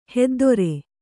♪ heddore